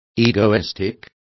Complete with pronunciation of the translation of egoistic.